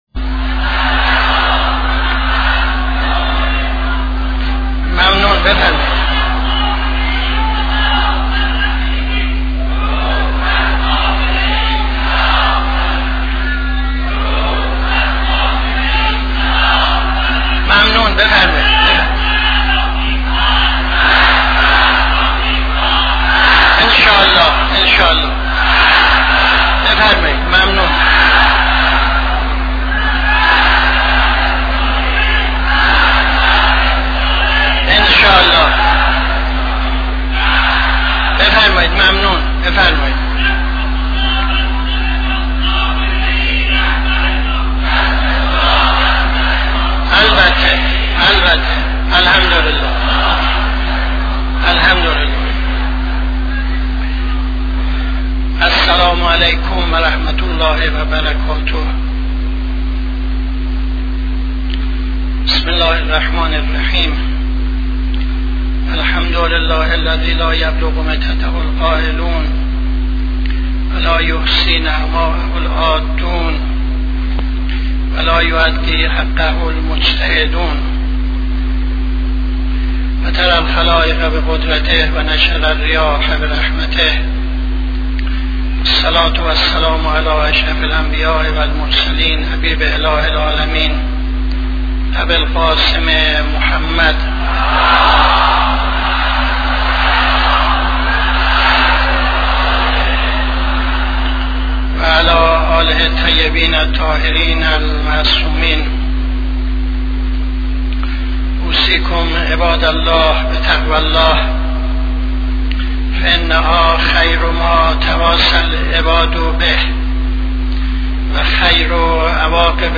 خطبه اول نماز جمعه 27-04-76